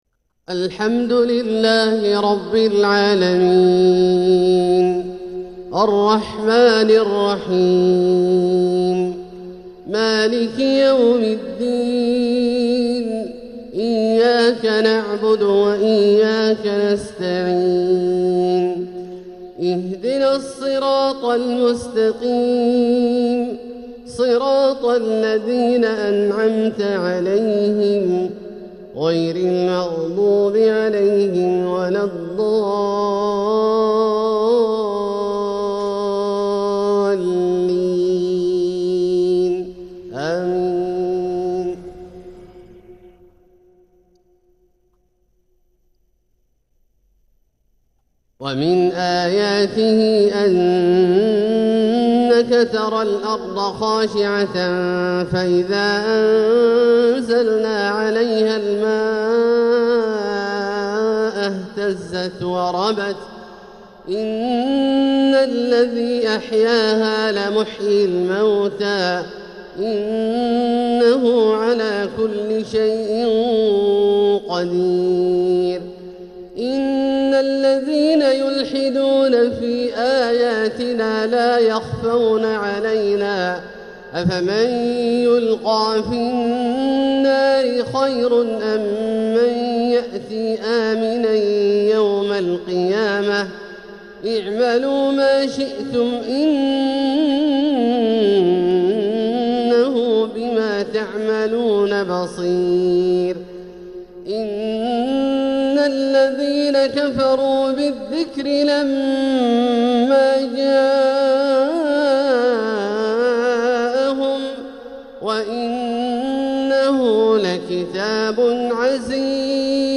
ما أندى ترتيله وحُسن تحبيره!